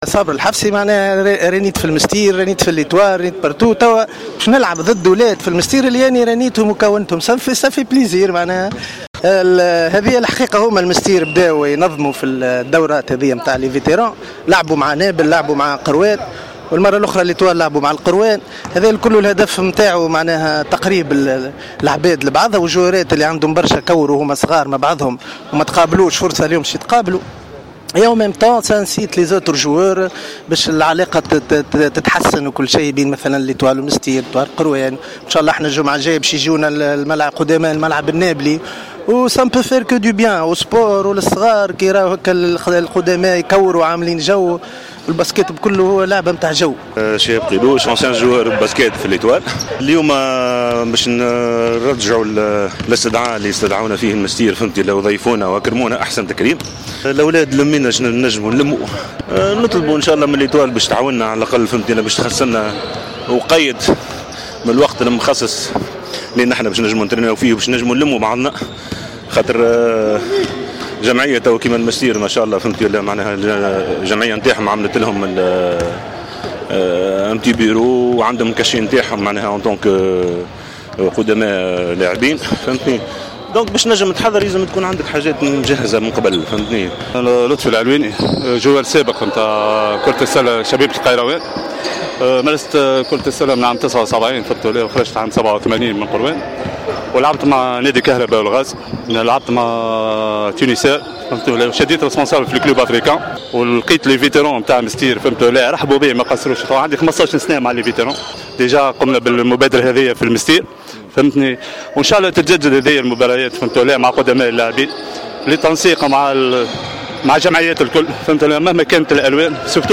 دارت يوم الأحد 20 أوت 2017 مقابلة طريفة في إطار ودي بين قدماء النجم الساحلي و الإتحاد المنستيري في كرة السلة بالقاعة الأولمبية بسوسة و قد شهدت حضور عدة نجوم من الفريقين من جيل السبعينات و الثمانينات .